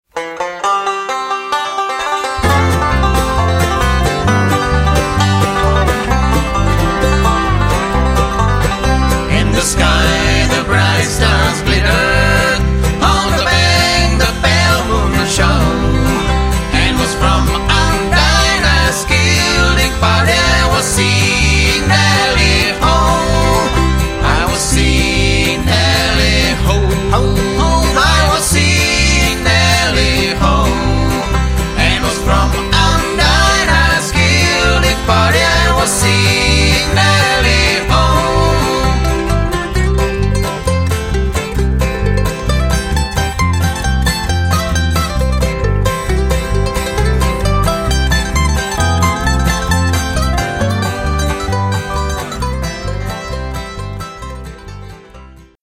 lead
baritone
tenor